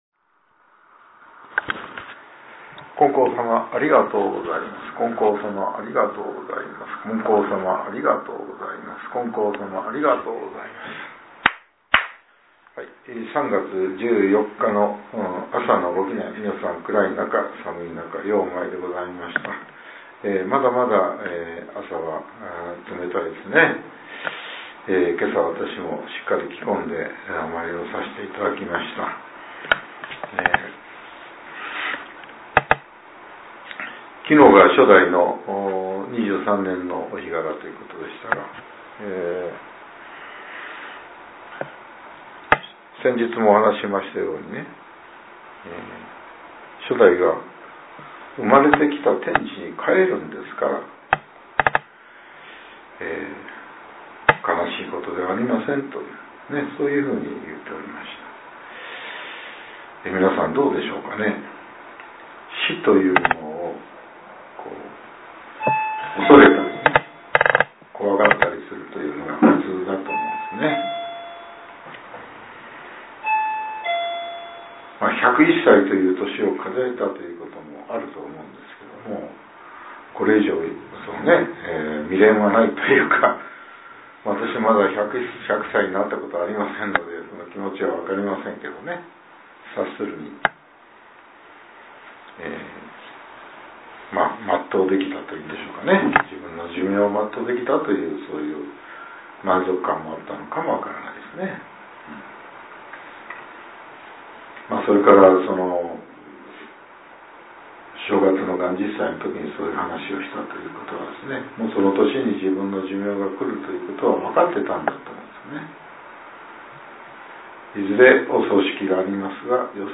令和８年３月１４日（朝）のお話が、音声ブログとして更新させれています。